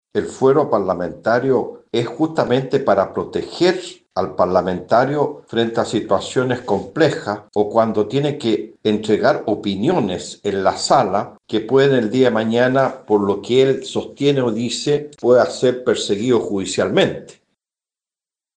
Así lo hizo saber el diputado socialista Jaime Naranjo.